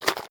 slime2.wav